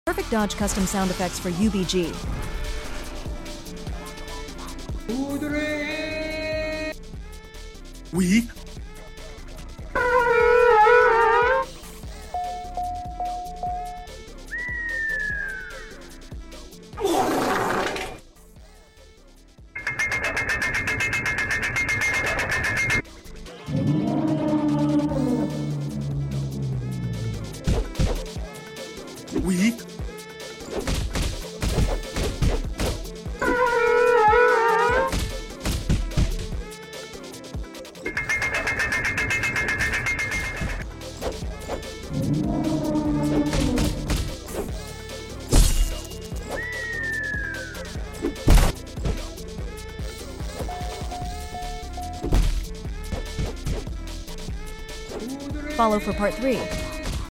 UNTITLED BOXING GAME CUSTOM SOUND EFFECTS PERFECT FOR PERFECT DODGE